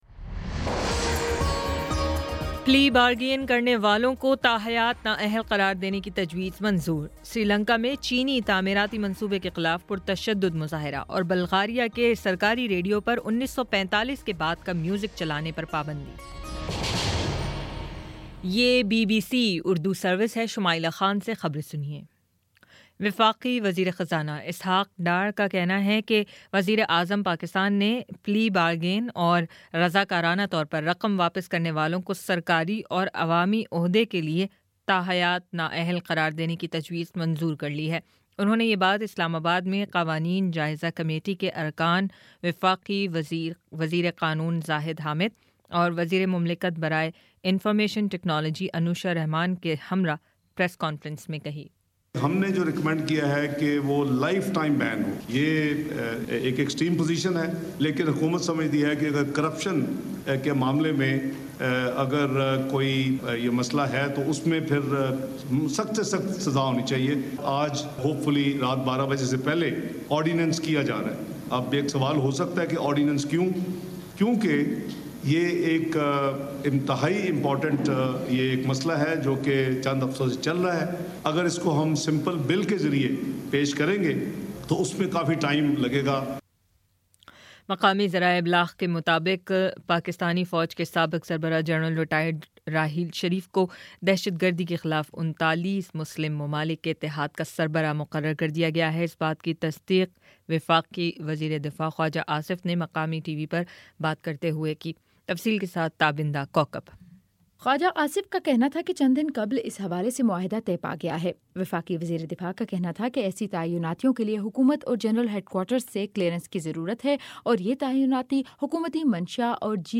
جنوری 07 : شام سات بجے کا نیوز بُلیٹن